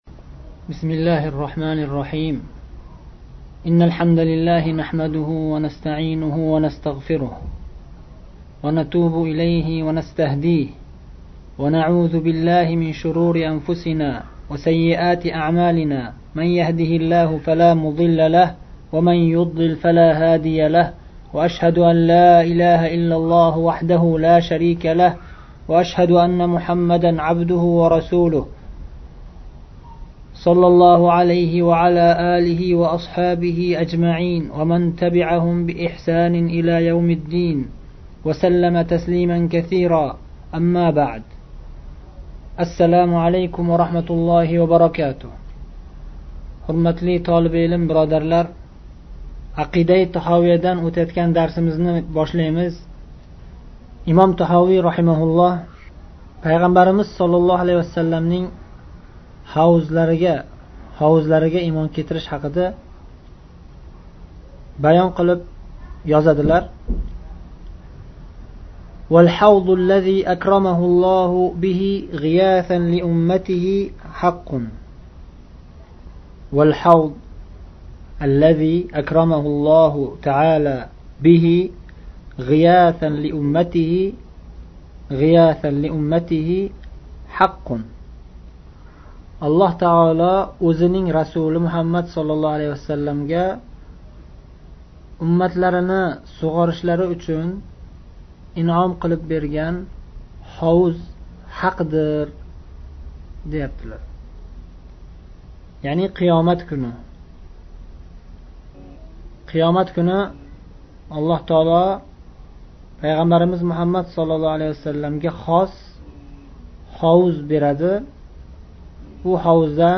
29 – ”Ақидаи Таҳовия” дарси